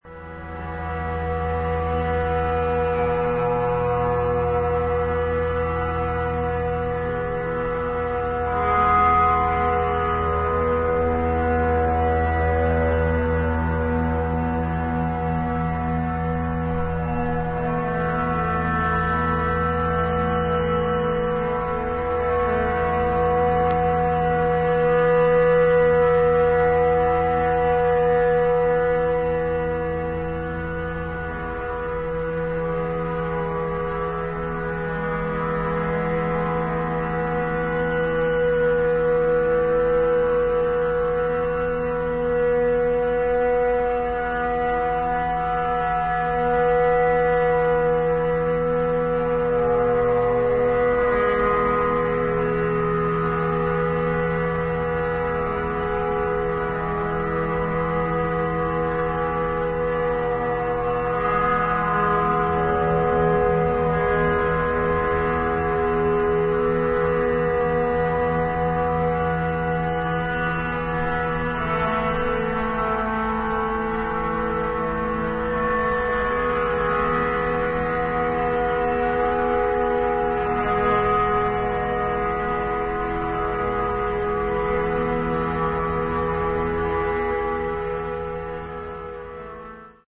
アンビエント感のあるドローン・サウンドが幾重にもレイヤーされ、深みのある重厚な世界観を披露している素晴らしい内容。